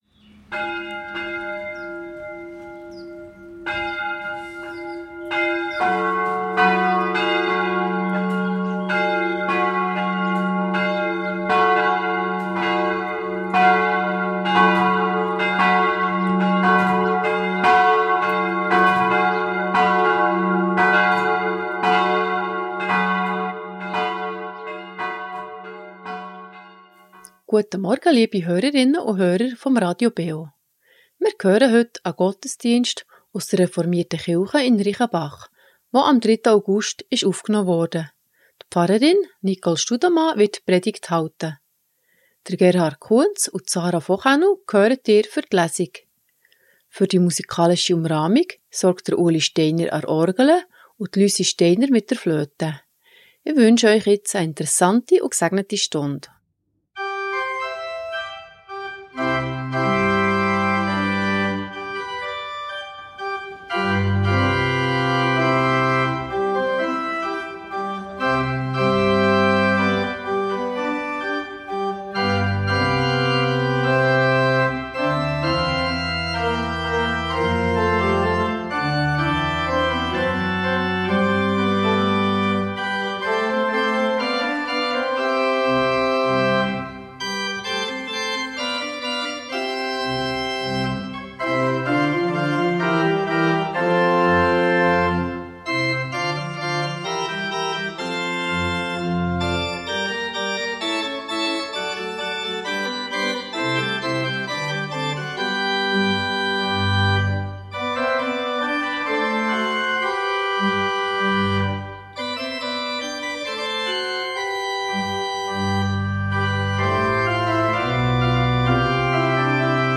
Reformierte Kirche Reichenbach ~ Gottesdienst auf Radio BeO Podcast